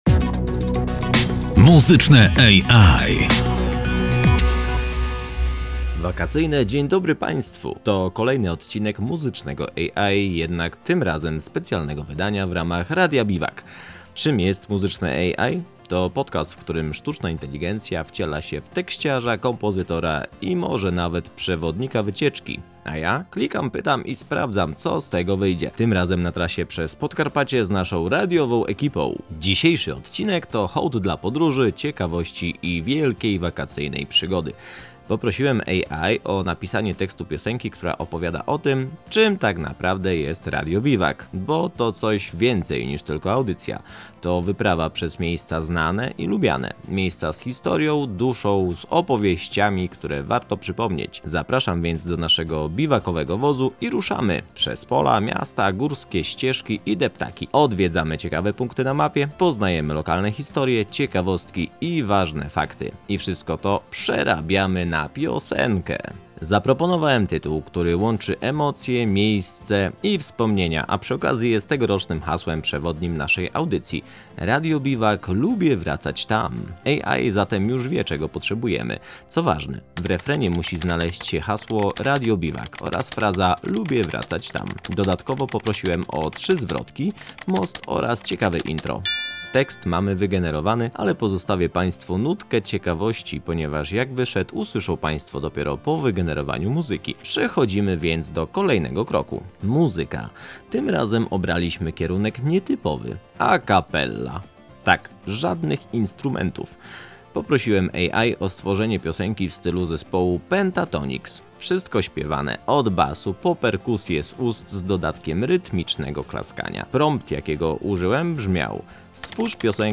Postawiliśmy na a cappella – inspirowaną brzmieniem Pentatonix. Bez instrumentów, za to z rytmicznym klaskaniem, basem z głębi gardła i harmoniami, które niosą letni uśmiech.